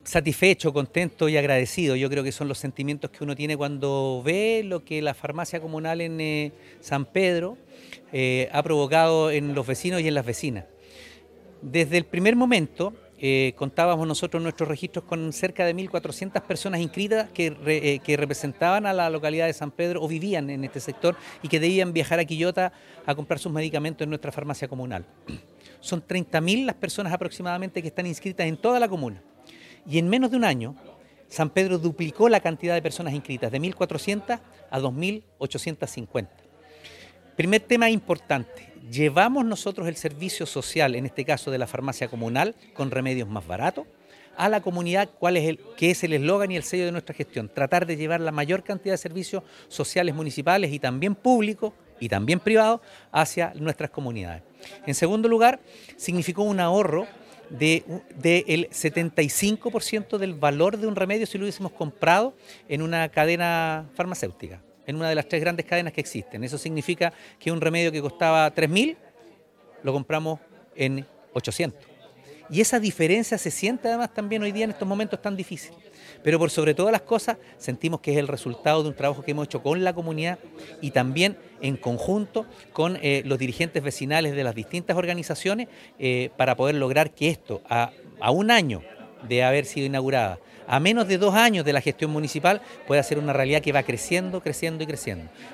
Así lo dio a conocer el alcalde Oscar Calderón Sánchez, tras participar en la ceremonia de conmemoración del primer año de funcionamiento de la Farmacia Comunal de San Pedro.